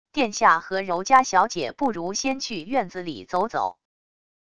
殿下和柔嘉小姐不如先去院子里走走wav音频生成系统WAV Audio Player